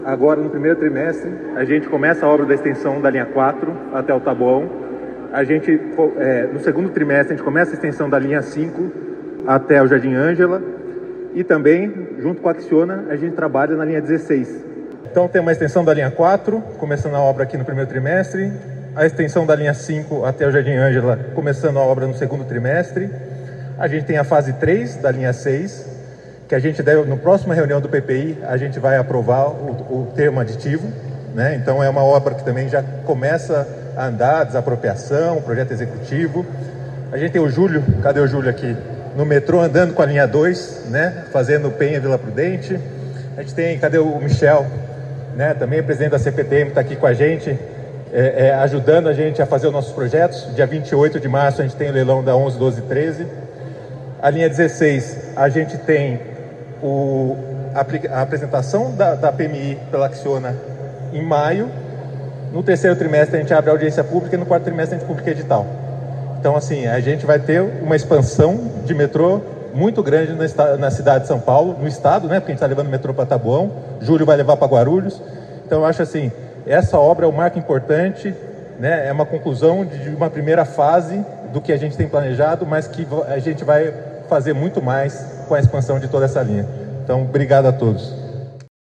Promessas foram feitas (algumas reafirmadas) pelo secretário de Parcerias em Investimentos, Rafael Benini, e Governador Tarcísio de Freitas, em evento da chegada do Tatuzão até Brasilândia e conclusão de abertura do túnel.
Secretário de Parcerias em Investimentos, Rafael Benini: